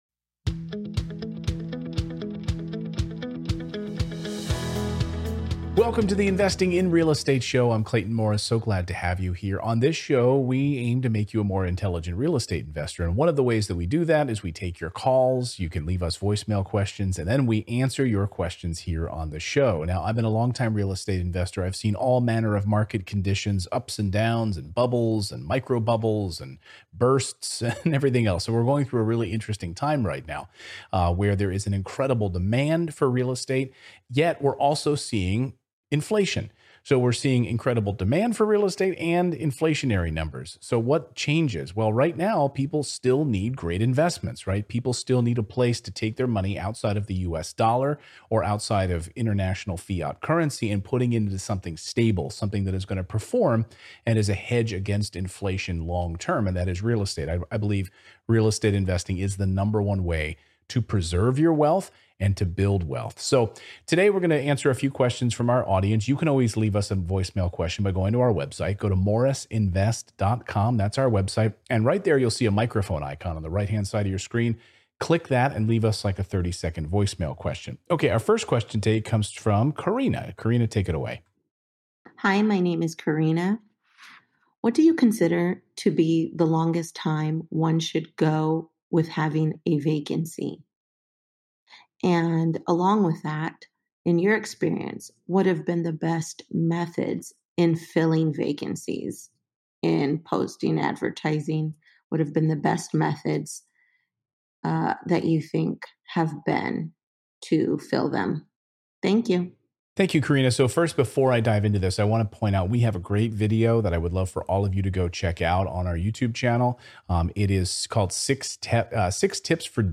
Our first caller on today's Q&A asked a great question: what is the best way to fill a vacancy? On today's show, I'm sharing some of my personal experiences and tips for dealing with vacancies.